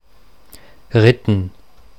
Standarddeutsche Form
[ritn]
Ritten_Standard.mp3